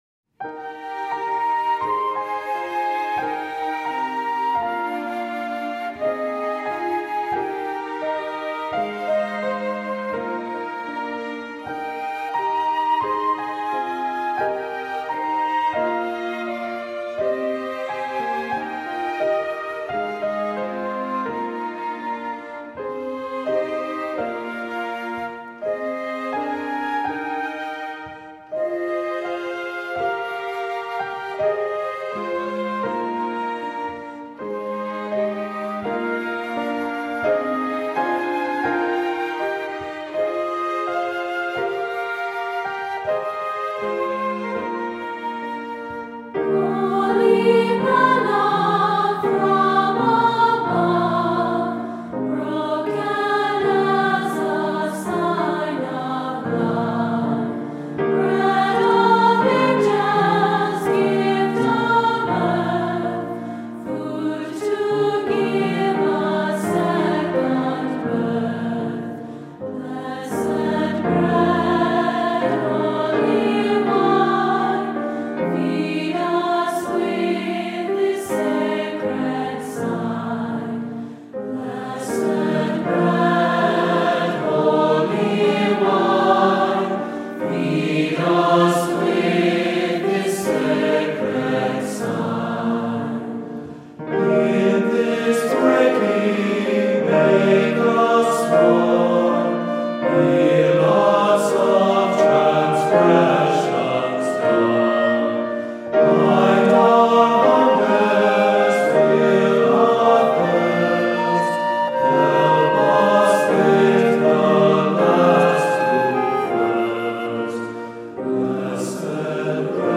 Voicing: "SAB"